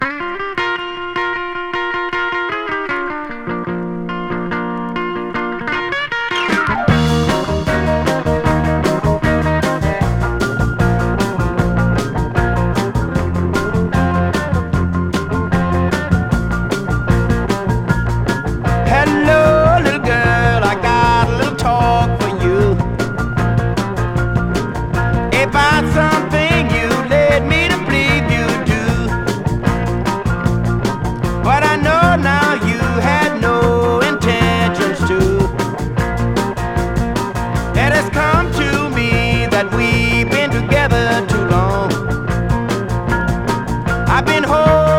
Rock, Rock & Roll　USA　12inchレコード　33rpm　Stereo